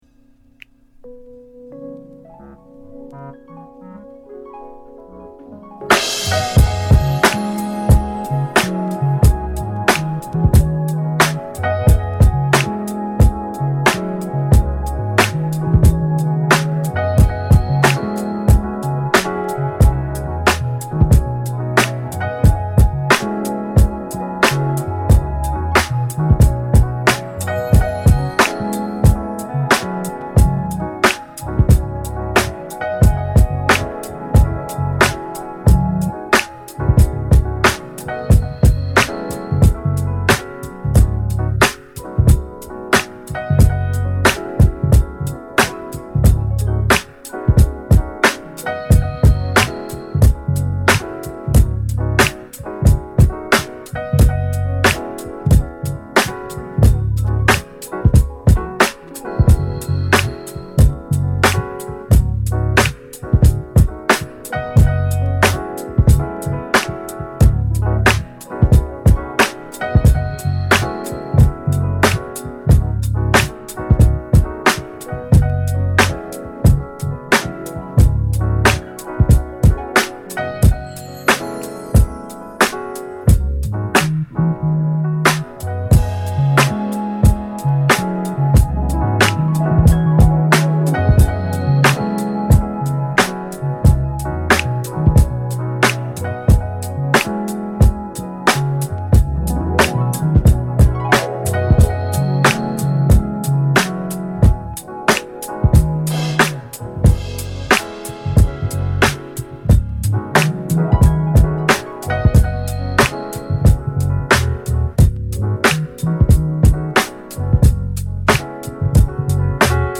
Mellow Groove , Mix CD